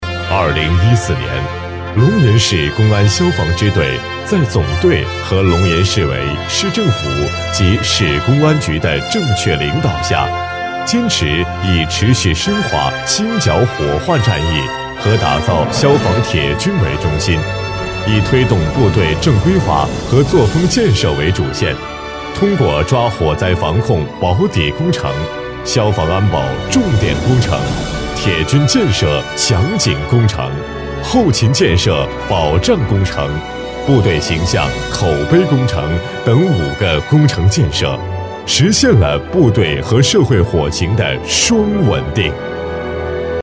成熟稳重 企业专题,人物专题,医疗专题,学校专题,产品解说,警示教育,规划总结配音
大气沉稳，成熟稳重男中音。